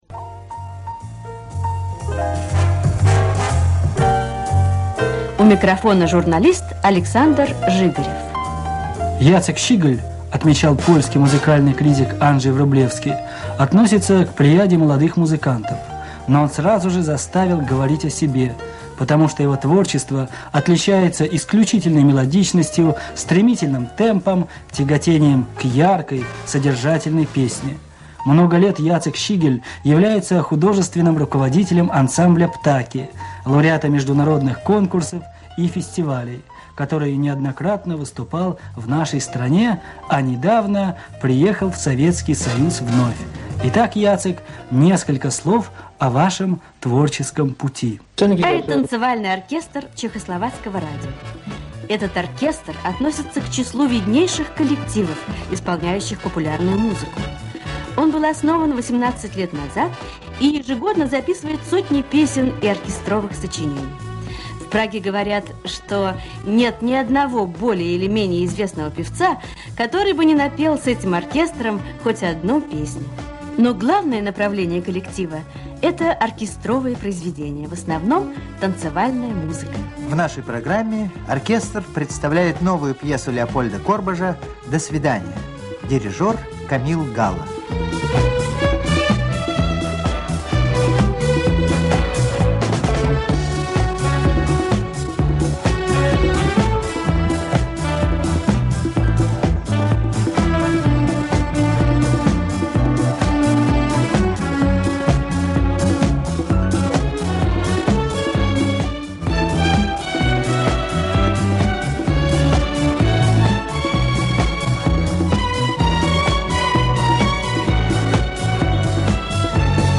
Всесоюзное радио. 65 минут песен и инструментала без разбивки на передачи (не понимаю, где и как разбивать, и что там за передачи). Оцифровка старой ленты.